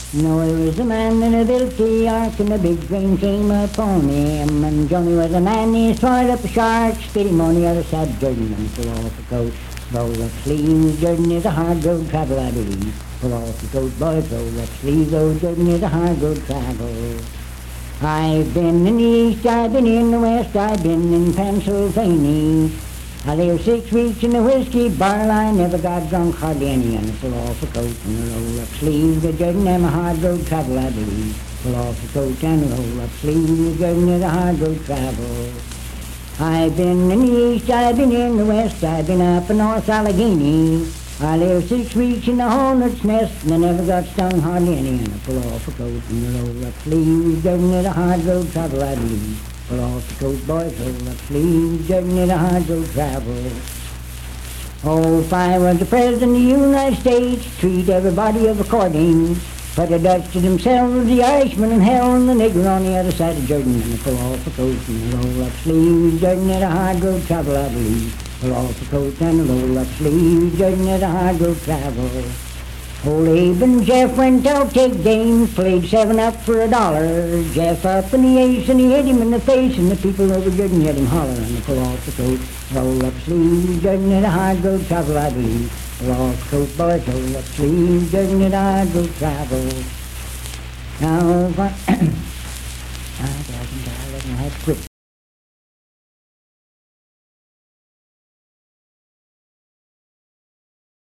Unaccompanied vocal music
Verse-refrain 5(4)&R(4). Performed in Sandyville, Jackson County, WV.
Voice (sung)